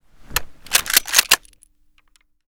mosin_unjam.ogg